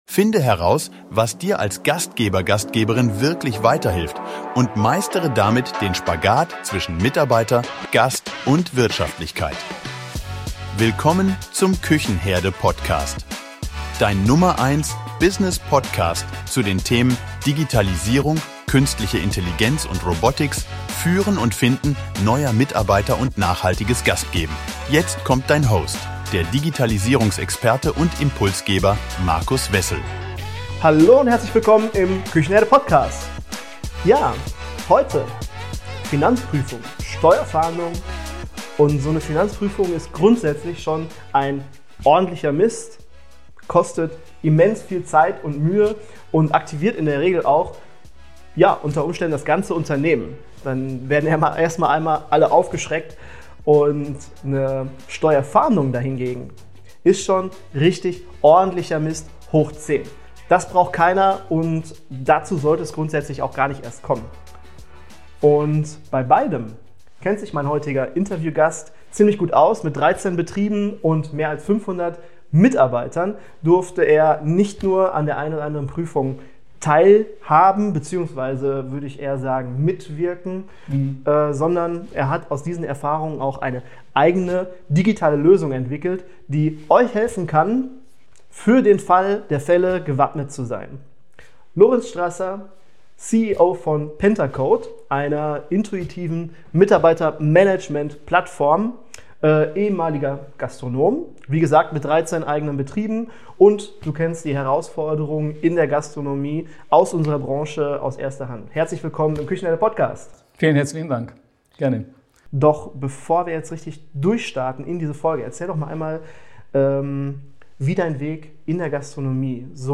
Im Interview erfährst du, warum ein ordentliches Kassenbuch überlebenswichtig ist, wie Umsatzschätzungen durch Kaffee-Kalkulation zu sechsstelligen Nachzahlungen führen können und weshalb die Gastronomie als Hochrisikobranche gilt.